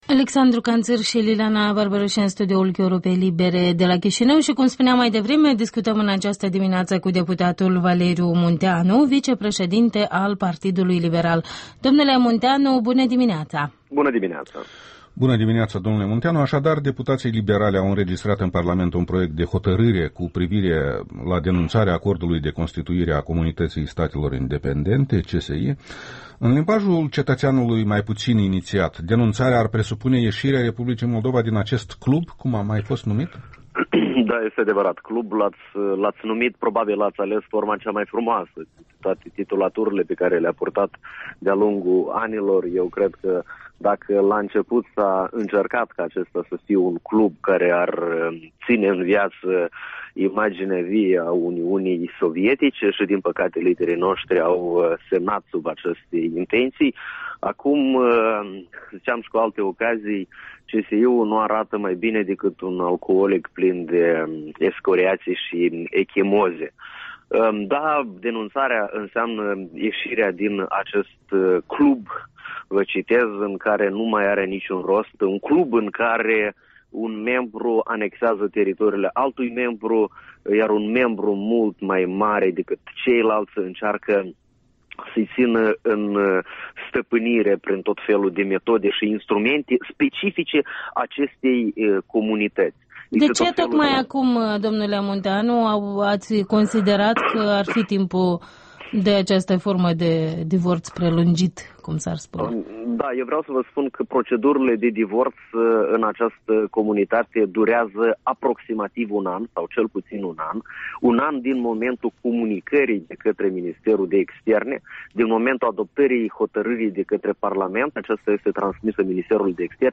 Interviul dimineții: cu deputatul liberla Valeriu Munteanu